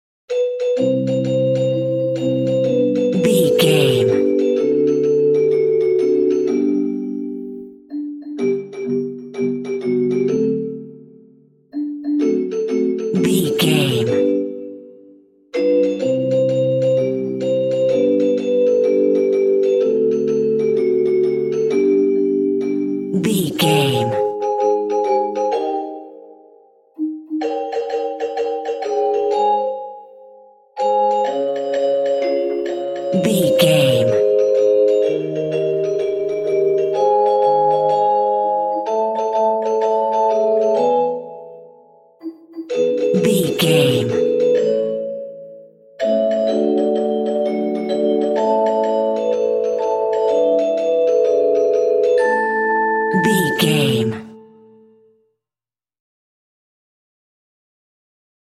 Ionian/Major
nursery rhymes
childrens music
Pizz Strings